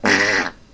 fart noise.mp3'